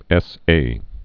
(ĕsā)